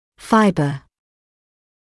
[‘faɪbə][‘файбэ]волокно; фибра; волокнистый